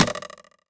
poly_explosion_arrow.wav